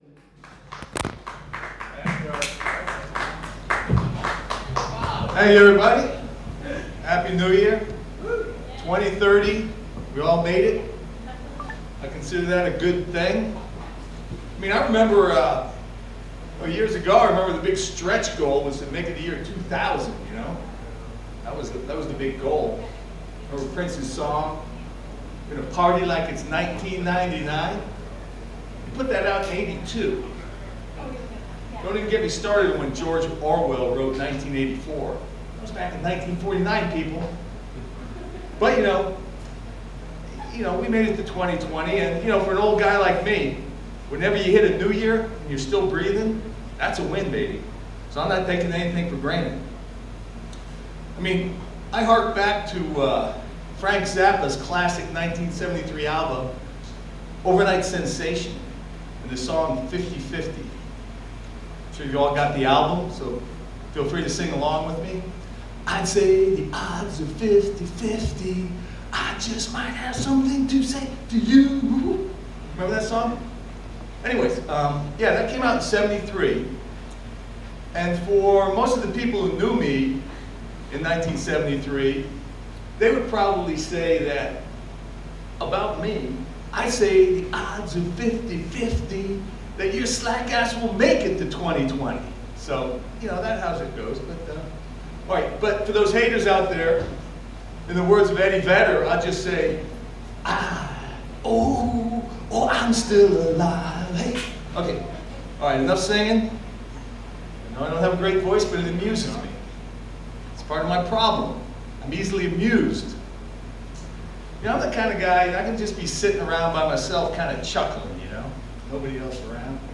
Stand-Up Comedy – Open Mic at the Downbeat Lounge – 14 Jan 2020